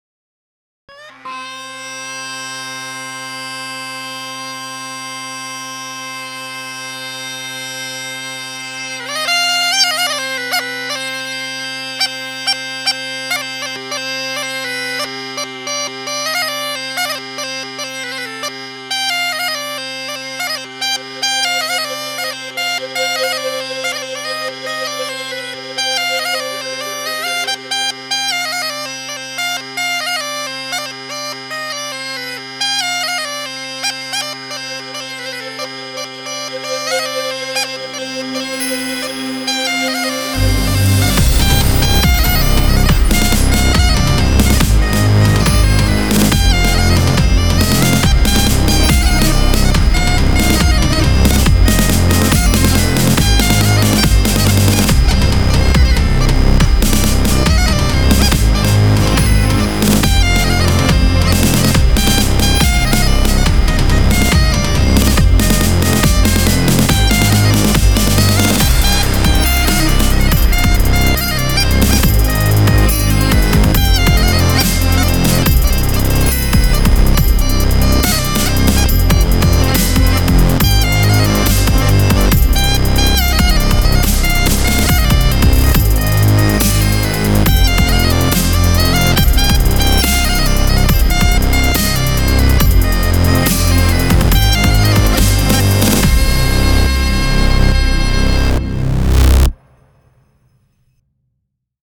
у аснову якой увайшоў папулярны ў Беларусі народны танец.
дудзе